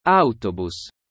A palavra “ônibus” em italiano é autobus. O legal é que a pronúncia é bem parecida com o português, o que já facilita bastante, especialmente se você fala uma língua latina.